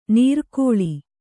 ♪ nīrkōḷi